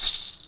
DrShake3.wav